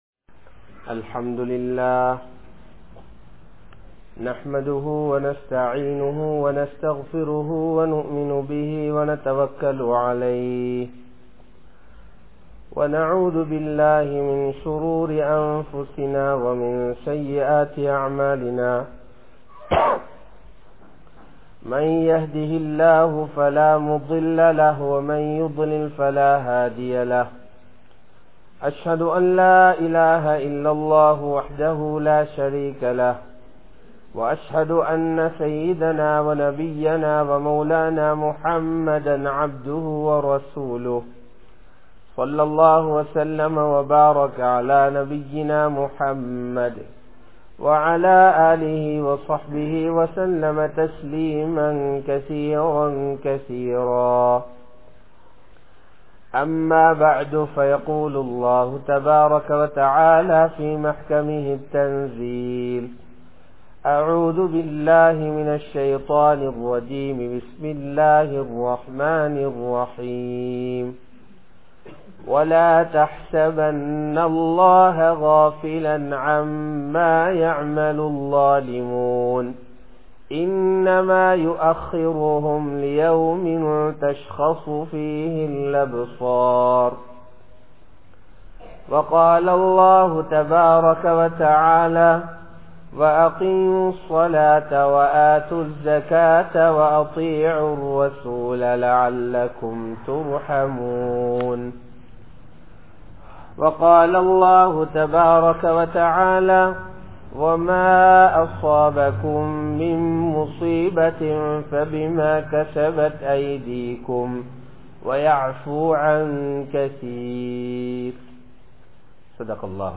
Pirachchinaihalukku Kaaranam Sietha Paavangal (பிரச்சினைகளுக்கு காரணம் செய்த பாவங்கள்) | Audio Bayans | All Ceylon Muslim Youth Community | Addalaichenai